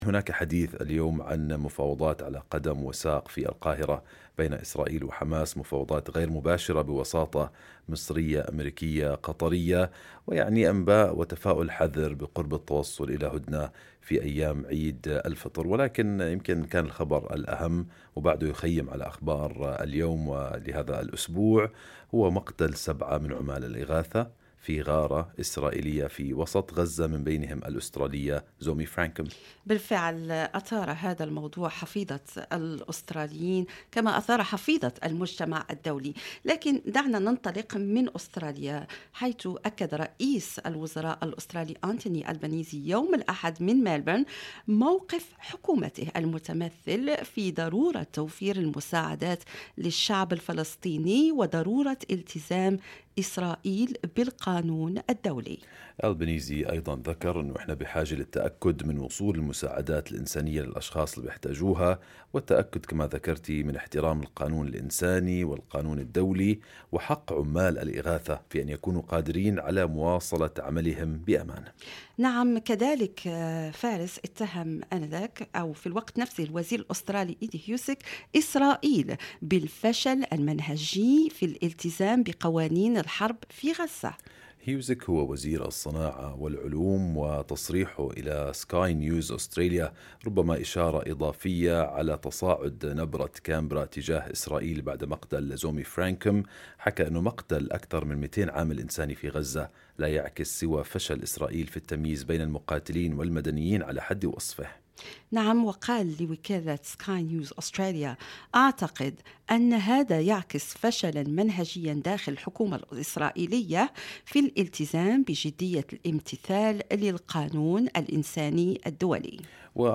تحدثنا مع السفير الفلسطيني لدى أستراليا، د. عزت عبد الهادي، والمتواجد حالياً في رام الله وسألناه عن رأيه بالإجراءات الأسترالية الأخيرة وطلبنا منه نقل صورة من أرض الواقع عما يحدث في الضفة الغربية.